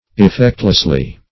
-- Ef*fect"less*ly , adv.